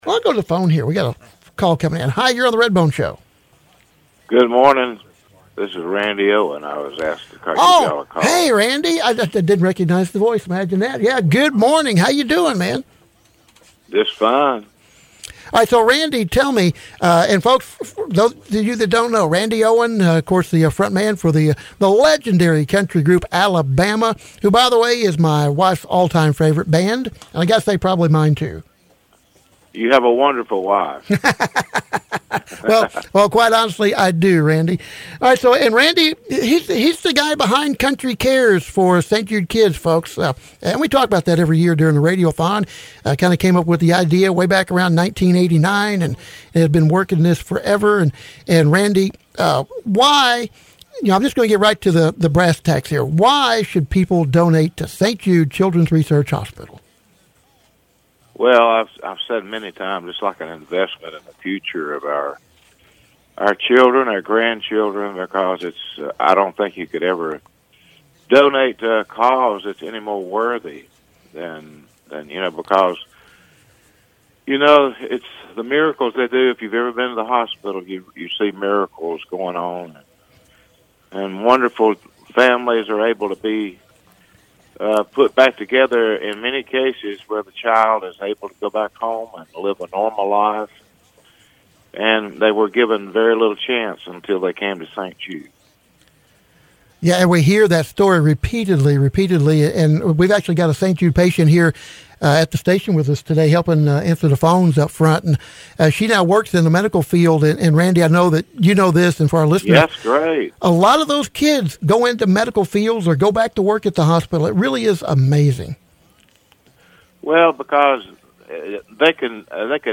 There were several highlights throughout the day Wednesday including a call from Randy Owen from Country Group “Alabama”.